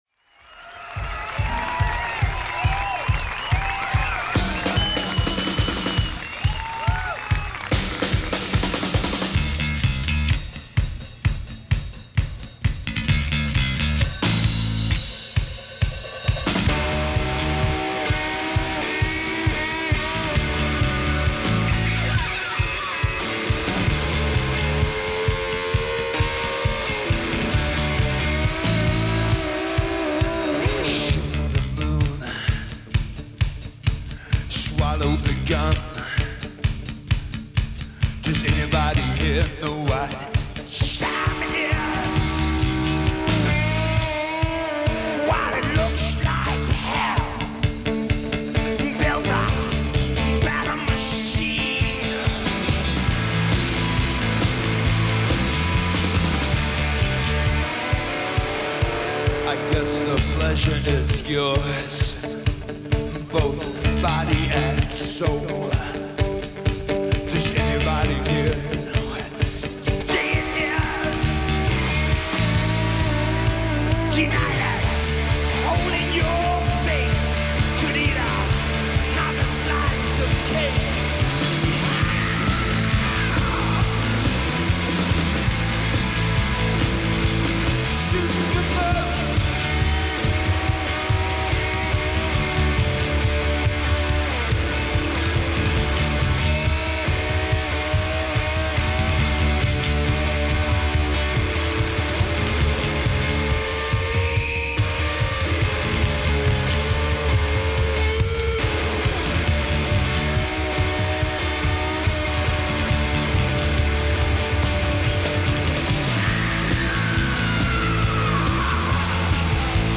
lead vocals, percussion
bass, vocals, keyboards
drums, percussion
guitars, vocals